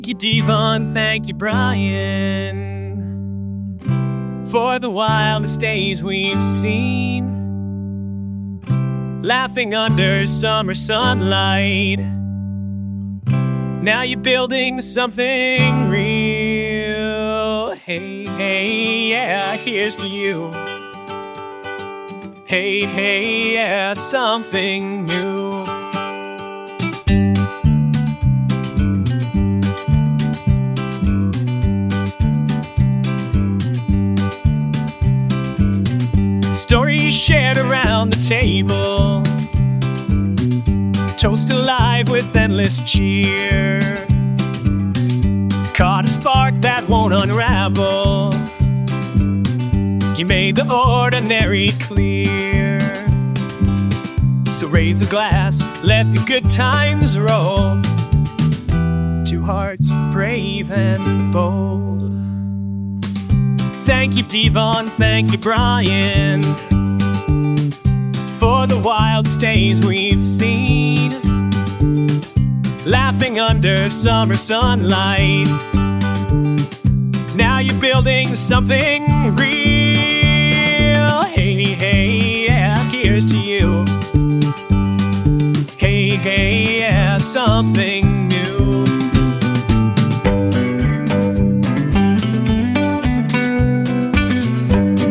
AI music created in the MusicKraze iPhone app
Tags: Rock